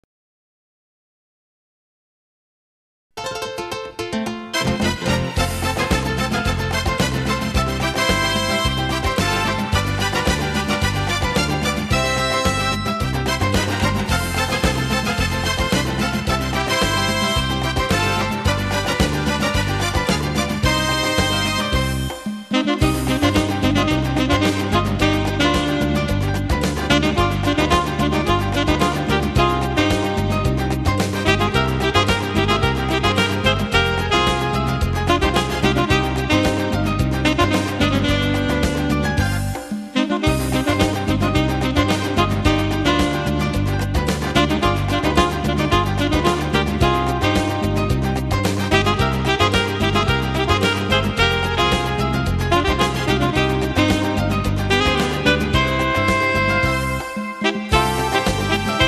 Rumba gitana
12 brani per sax e orchestra.